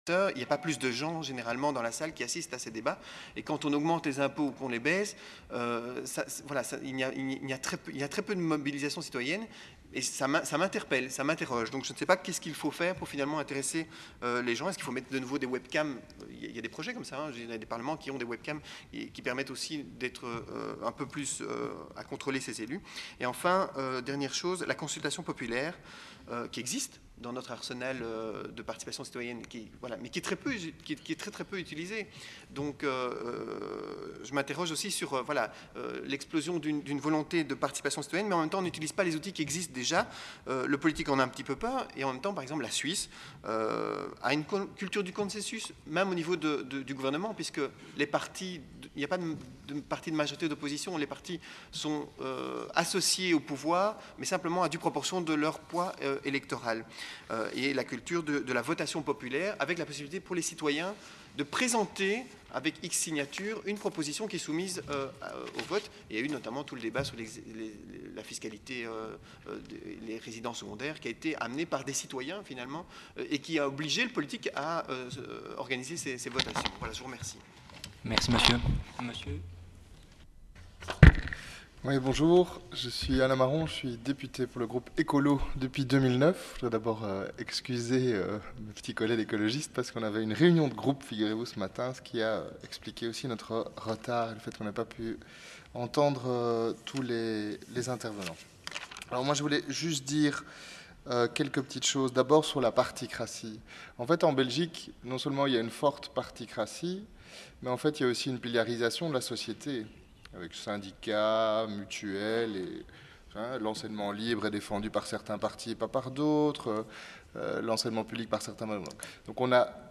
Colloque 23/01/2014 : SP 6
6. SP_ débat_audio.mp3 — 28.1 MB